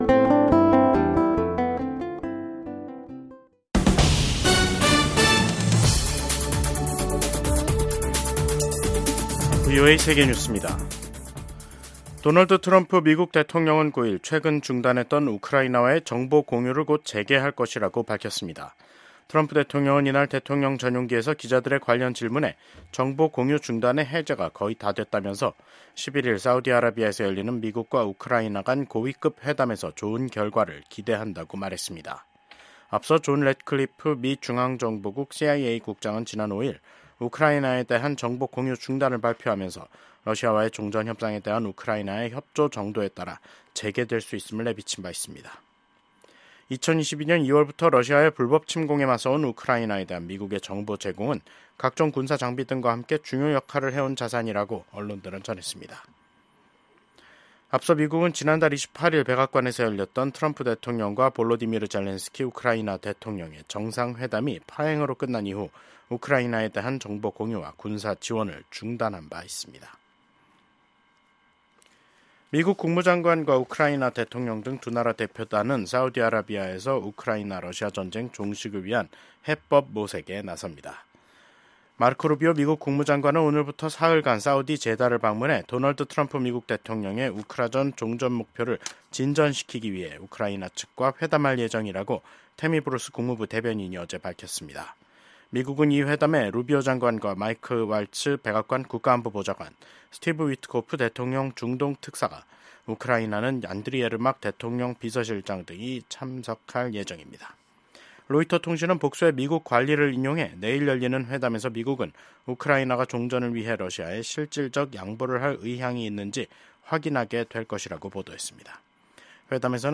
VOA 한국어 간판 뉴스 프로그램 '뉴스 투데이', 2025년 3월 10일 2부 방송입니다. 북한이 오늘 근거리 탄도미사일(CRBM)로 추정되는 수발의 발사체를 서해상으로 쐈습니다. 미국의 중국 전문가들과 전직 관리들이 트럼프 행정부가 한국, 일본과 협력해 북러 군사협력을 막기 위해 중국이 건설적 역할을 할 것을 압박해야 한다고 제안했습니다. 북러 군사협력이 날이 갈수록 심화되는 가운데 북한과 러시아 접경 지역에서 열차 움직임이 크게 증가하고 있습니다.